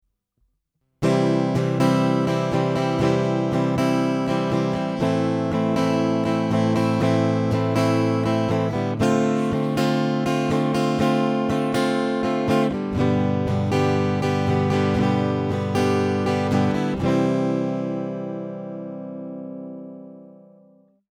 Multiac Jazz with COUNTRYMAN DI
DI直接で録音するとおもいっきりライン録り臭い音がします。